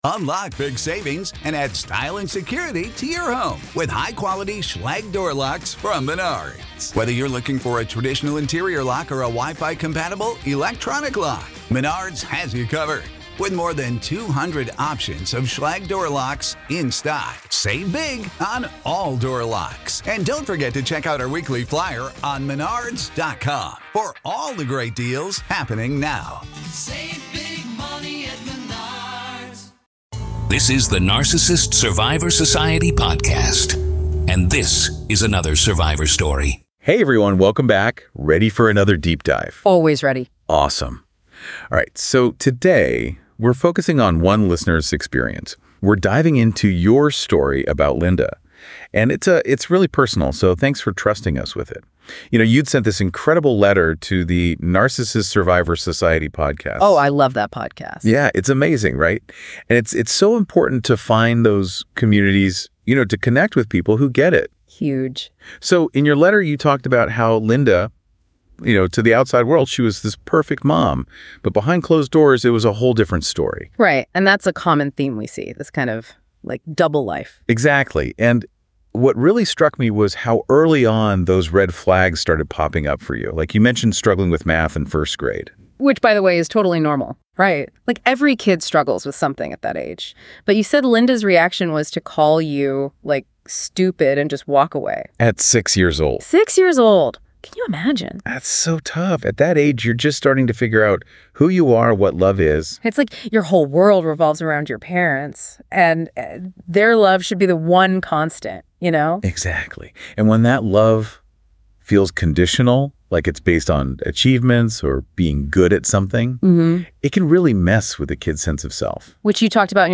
In this episode, a woman reflects on her life with a narcissistic mother who used guilt, lies, and control to manipulate her at every turn. From childhood through adulthood, she shares the emotional toll of living under her mother’s toxic influence.